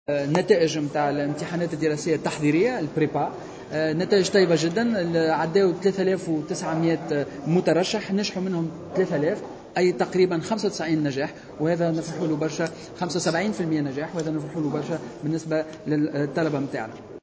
وأضاف في تصريح لمبعوث الجوهرة اف ام إلى مجلس نواب الشعب على هامش جلسة استماع له، أن 3 الاف مترشح تمكنوا من النجاح في امتحانات المعاهد التحضيرية للهندسة، من بين 3700 طالب اجتازوا الامتحانات.